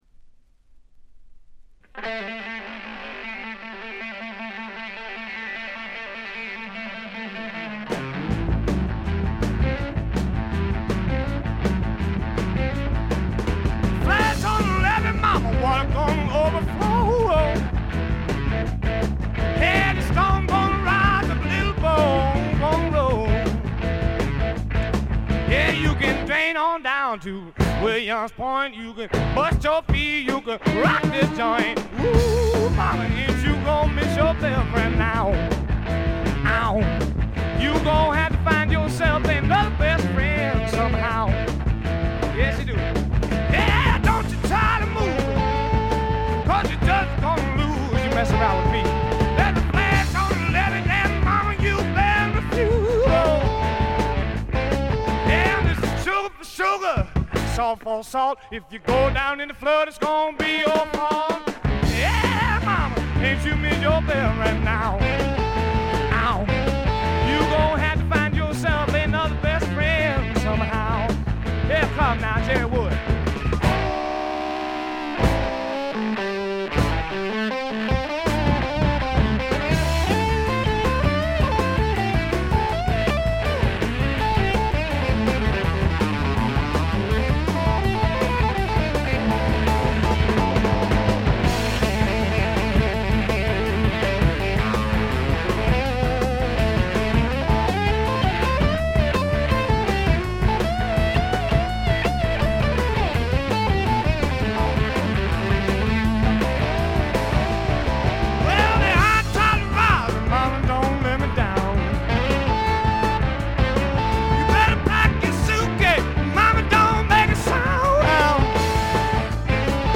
ほとんどノイズ感無し。
ブルース･ロックとスワンプ・ロックを混ぜ合わせて固く絞ったような最高にグルーヴィでヒップなアルバムです。
試聴曲は現品からの取り込み音源です。